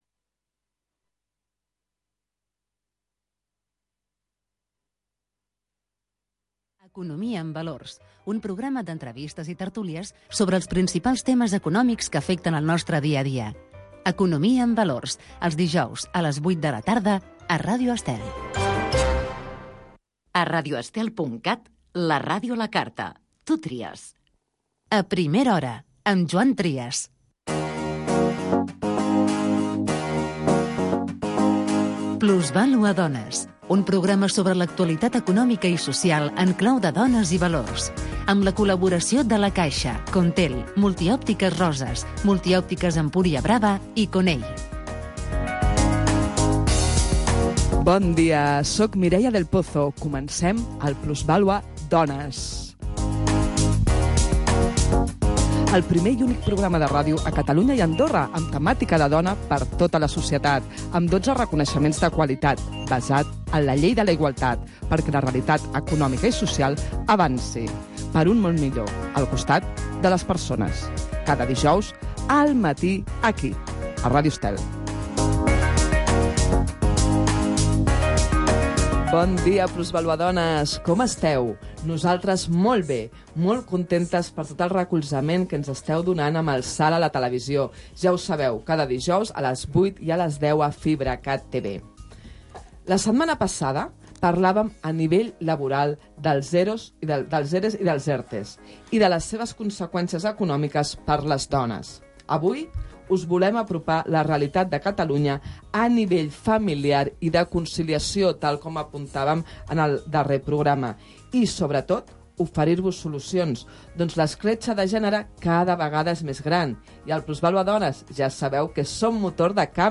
Entrevista a la degana en el programa "Plusvàlua dones" de Ràdio Estel